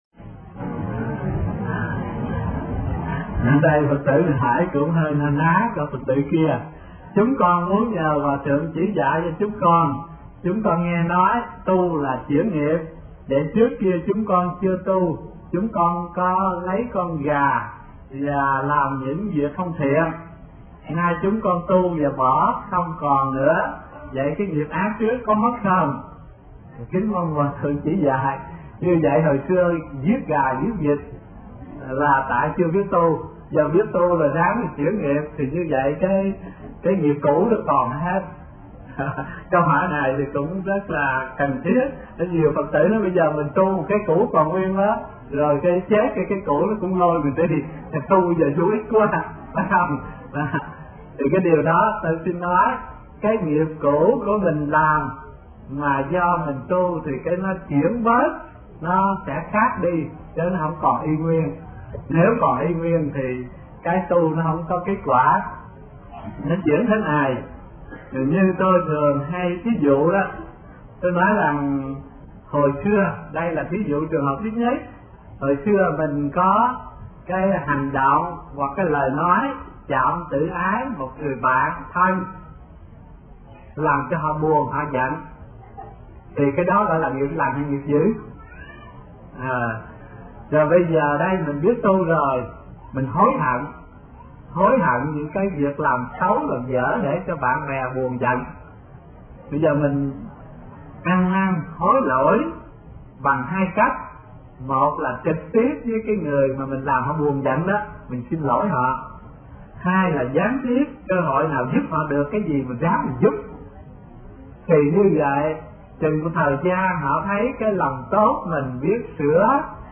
Xưa Làm Việc Ác, Nay Tu Thì Nghiệp Ác Có Mất Không – Tham vấn HT Thích Thanh Từ 35